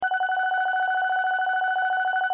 - lottery.mp3 - lottery spinning music